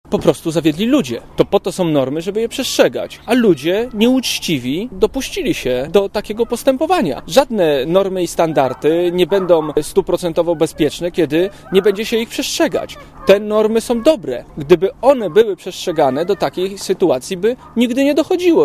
Mówi minister rolnictwa